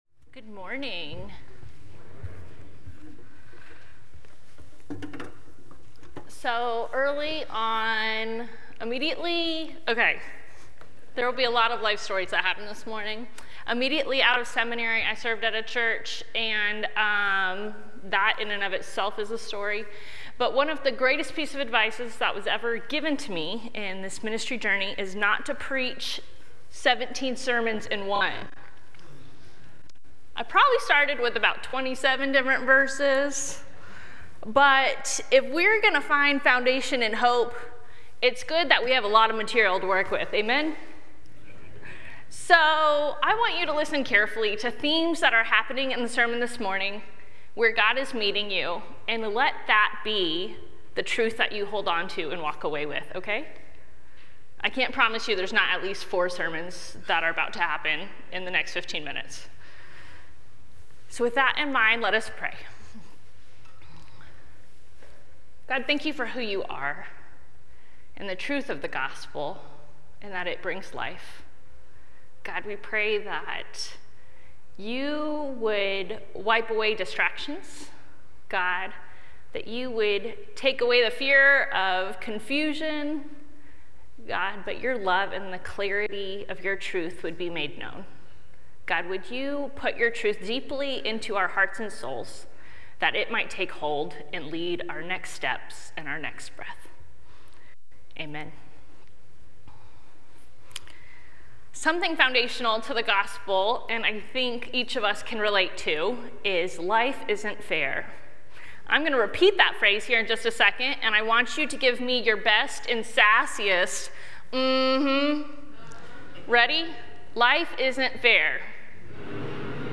Sermon Reflections: When you think of a past struggle that you survived, what truth emerged from that experience that continues to shape your life today?